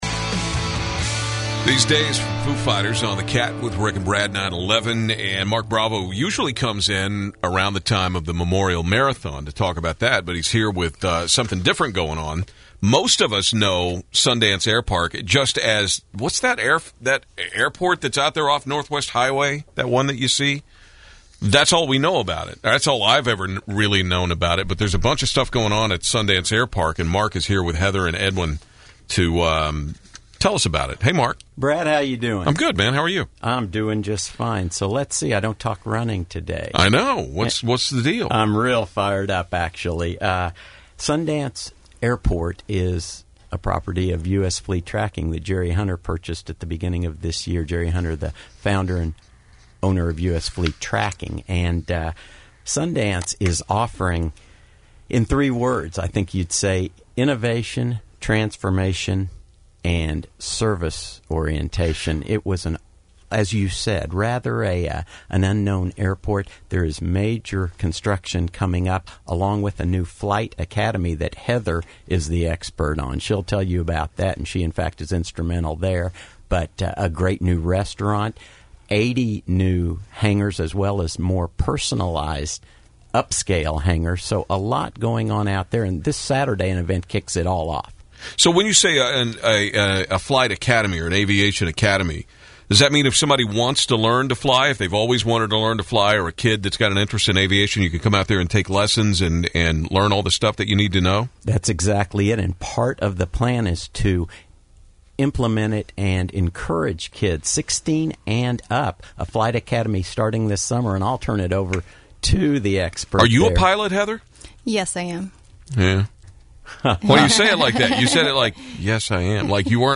KATT-FM interview about 2013 Discover Aviation